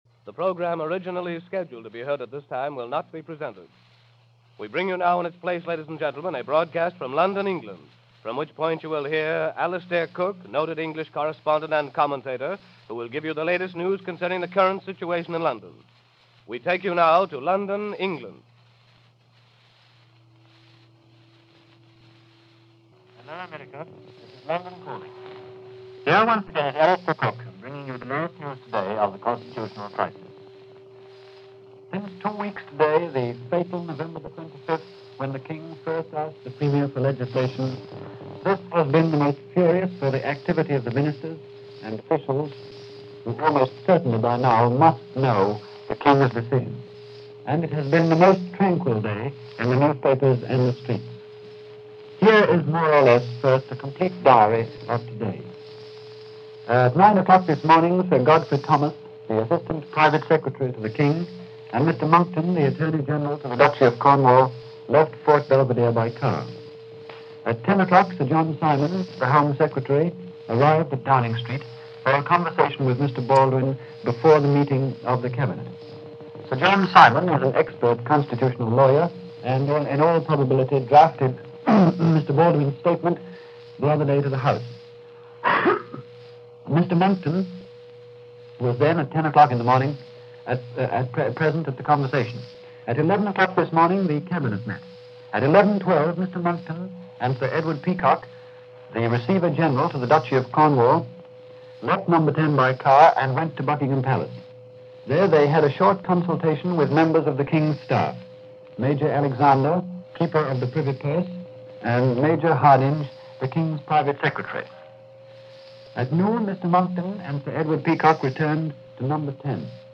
December 9, 1936 – BBC/NBC – Alistair Cooke and Bulletins from London